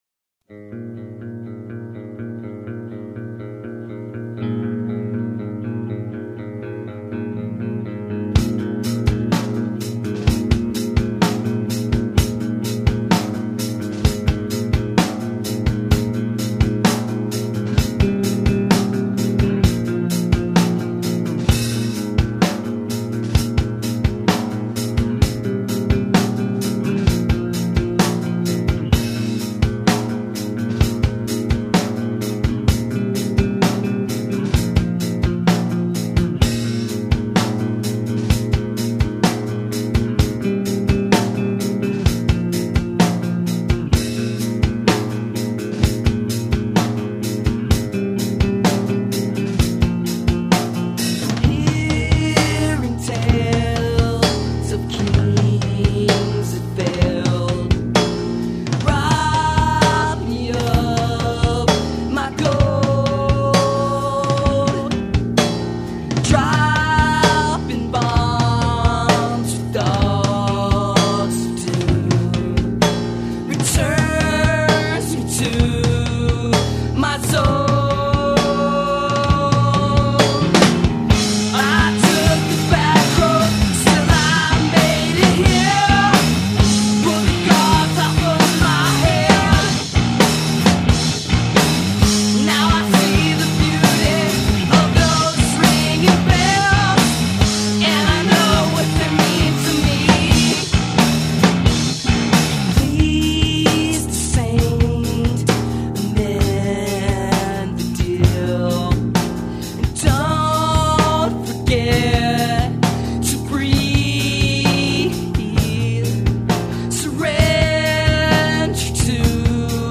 People called us "math-rock"; people sort of liked us.